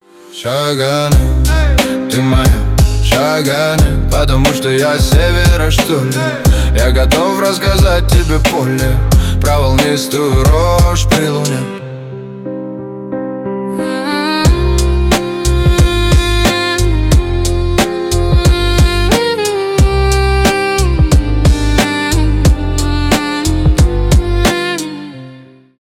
романтические , русские , стихи , поп